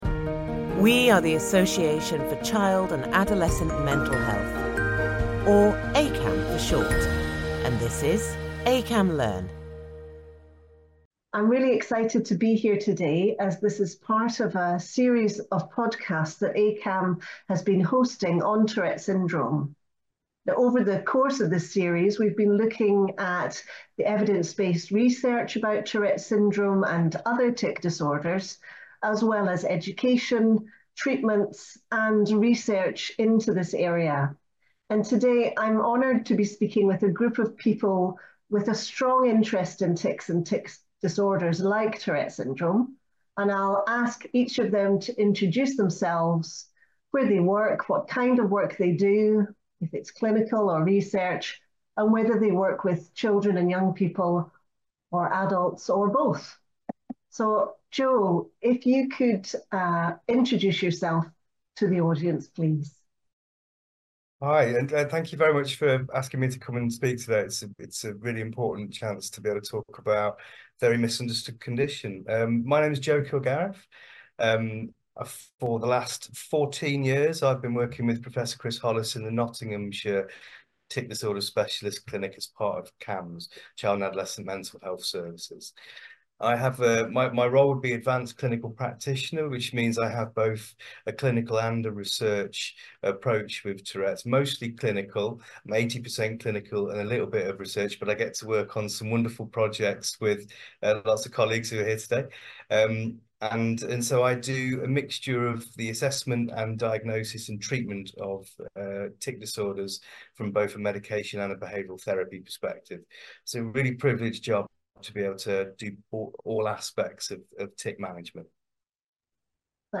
Understanding Tic Disorders: A Round Table on Diagnosis, Treatment, and Research